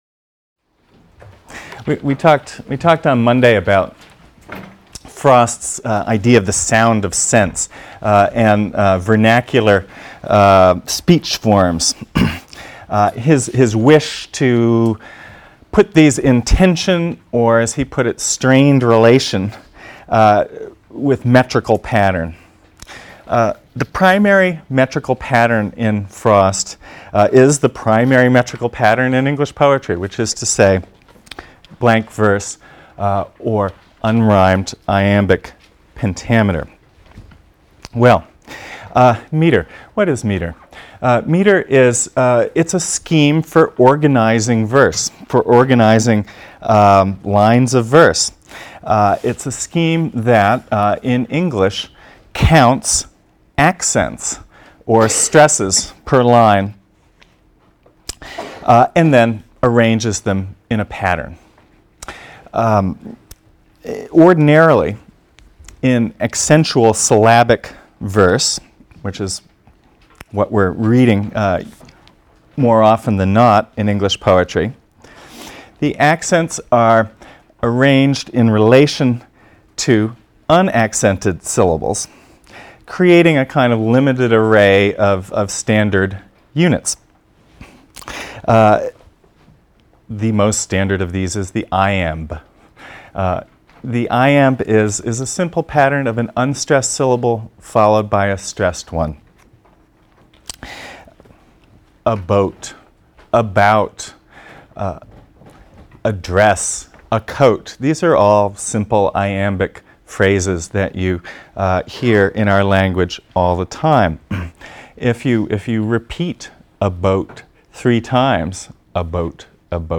ENGL 310 - Lecture 3 - Robert Frost (cont.)